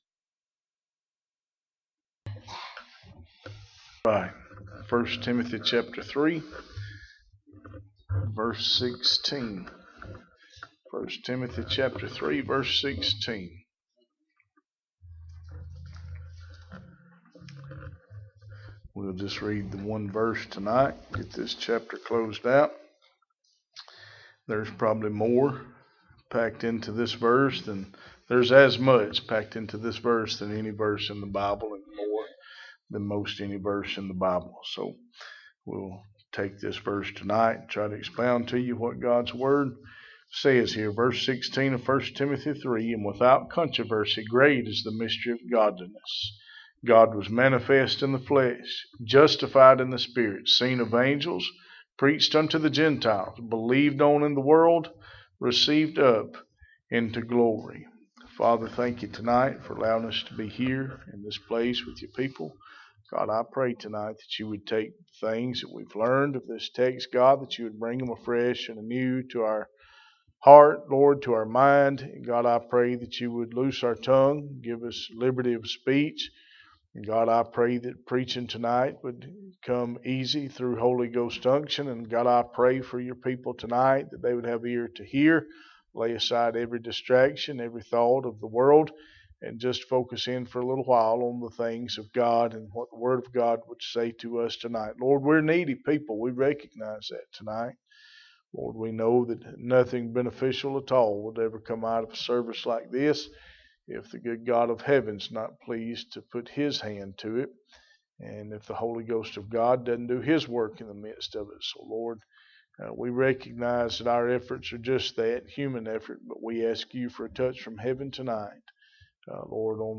1 Timothy Passage: 1 Timothy 3:16 Service Type: Sunday Evening « The Question